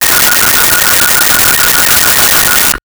Telephone Ring 01
Telephone Ring 01.wav